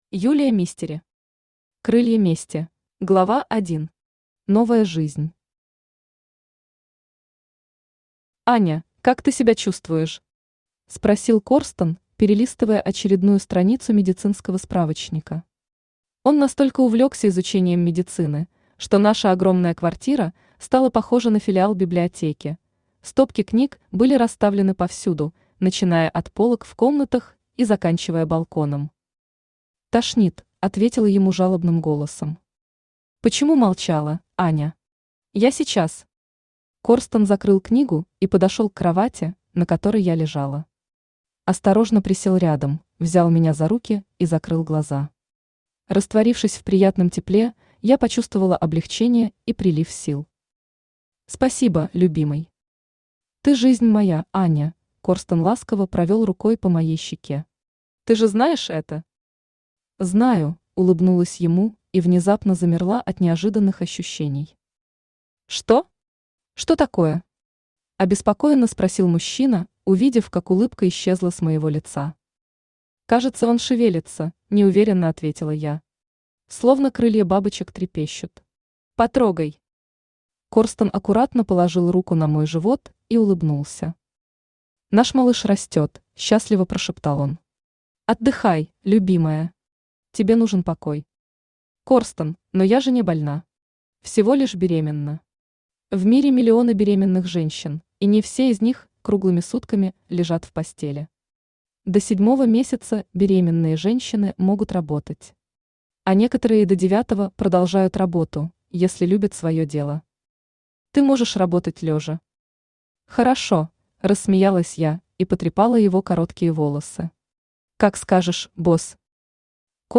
Аудиокнига Крылья мести | Библиотека аудиокниг
Aудиокнига Крылья мести Автор Юлия Мистери Читает аудиокнигу Авточтец ЛитРес.